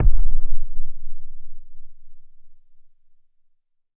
explosion_far_distant_01.wav